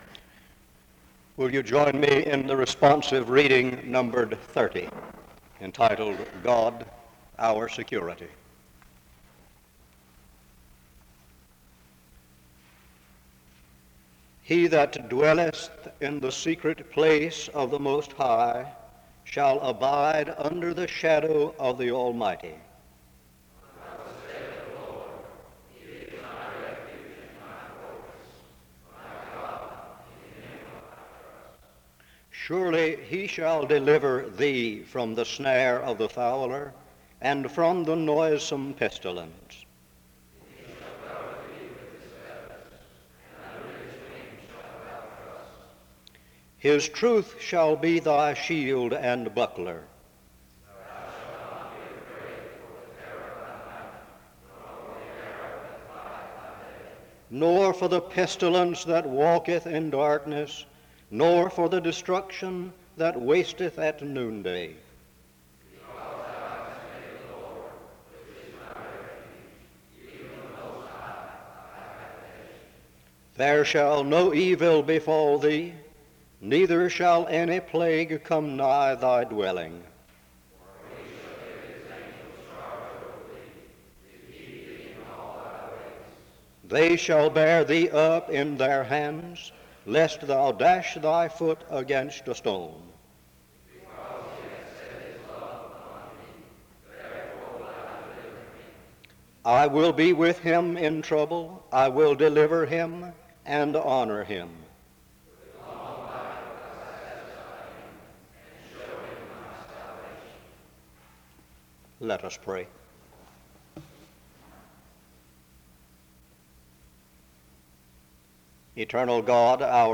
The service begins with a responsive reading and prayer (0:00-3:44).
Audio distorted from 14:22-15:19.